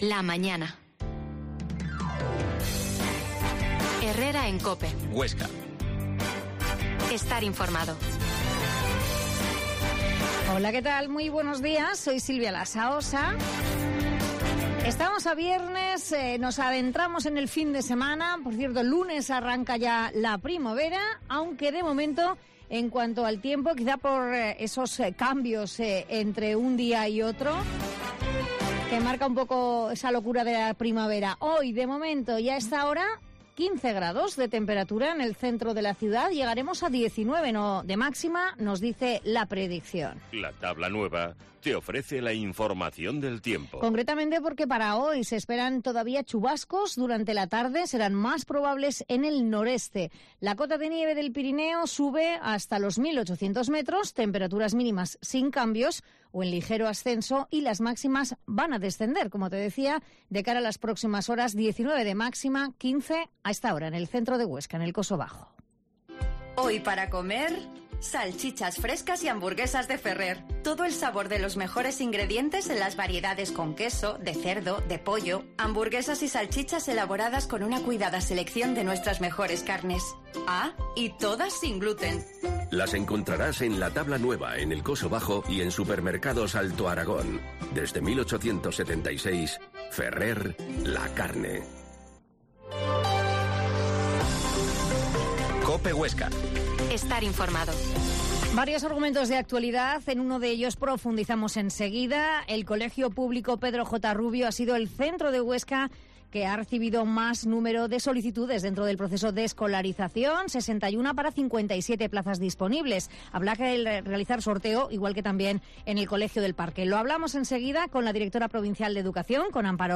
Herrera en COPE Huesca 12.50h Entrevista a la Directora provincial de educación, Amparo Roig